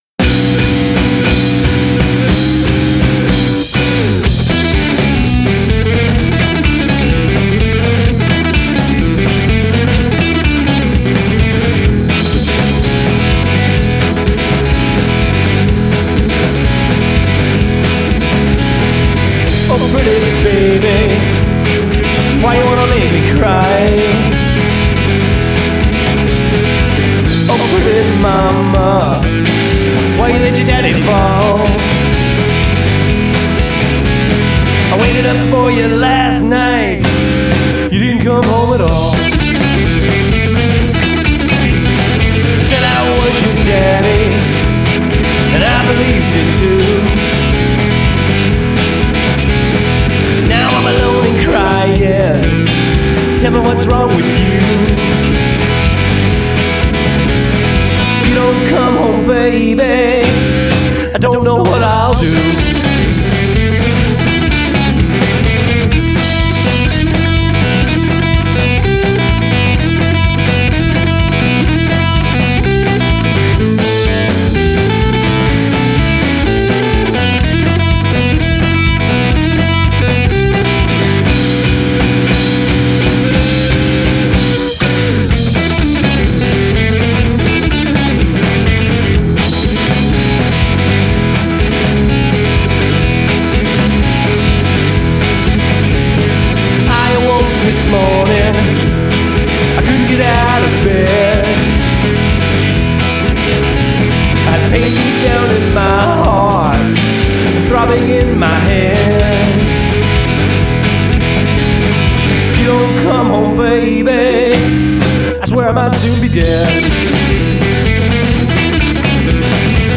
First and most raw recording from these guys out of Seattle.